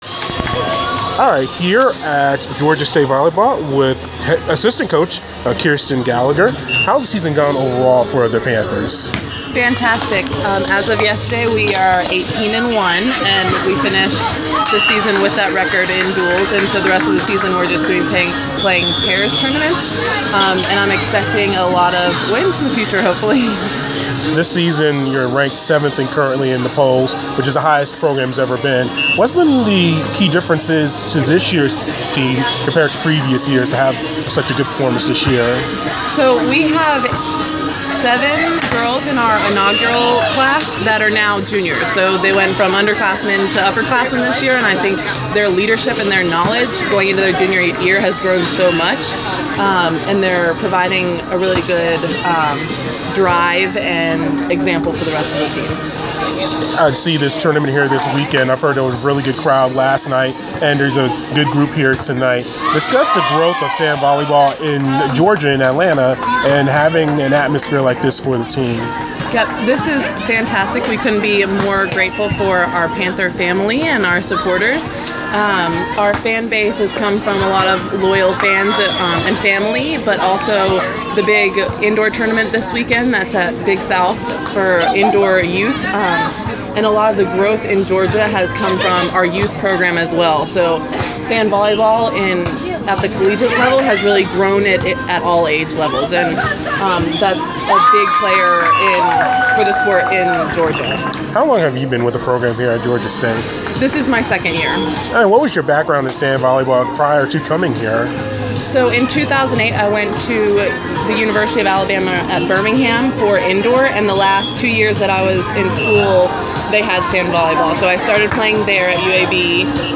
Inside the Inquirer: Exclusive interview